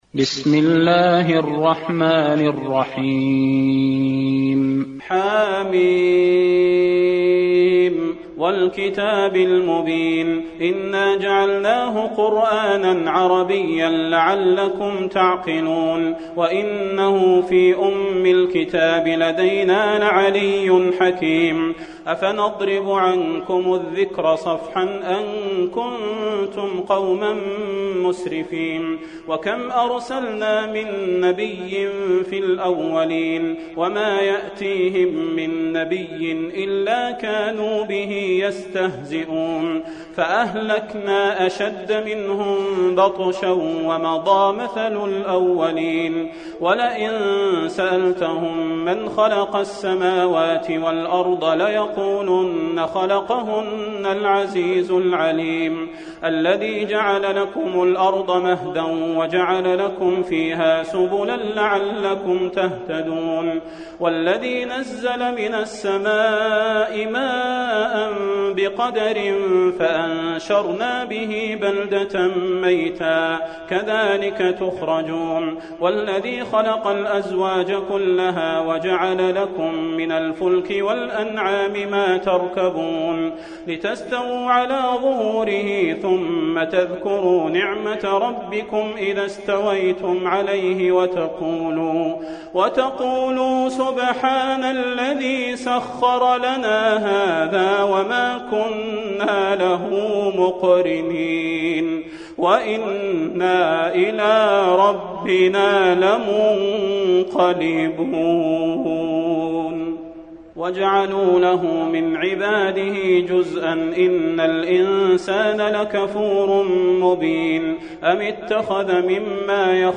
المكان: المسجد النبوي الزخرف